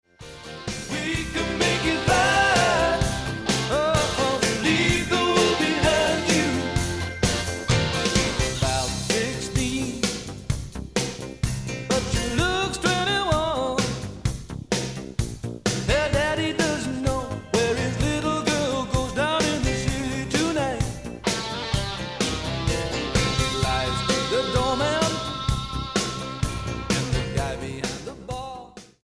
STYLE: Pop
with elements of soul